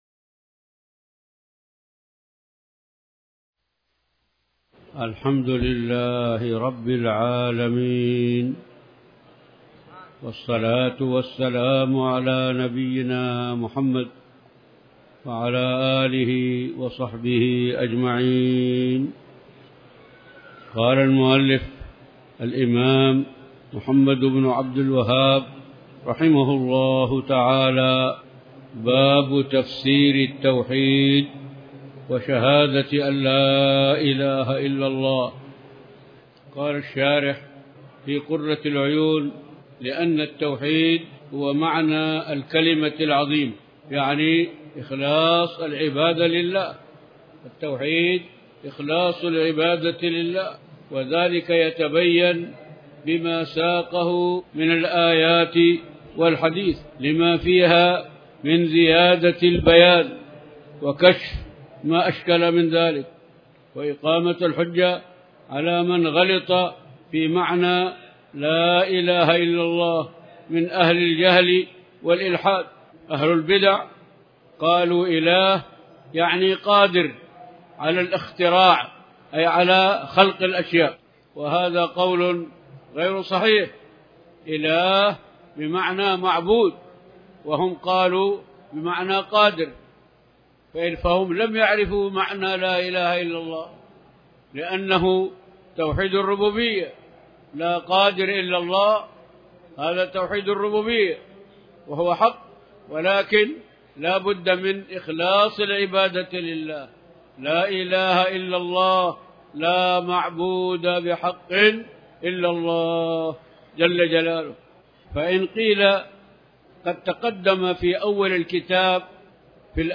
تاريخ النشر ٢٠ محرم ١٤٤٠ هـ المكان: المسجد الحرام الشيخ